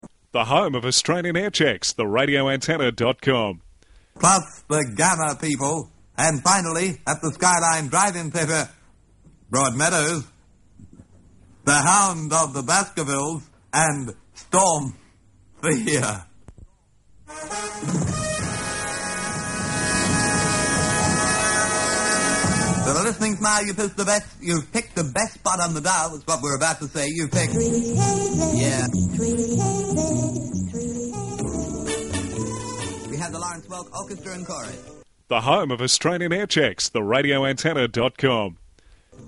RA Aircheck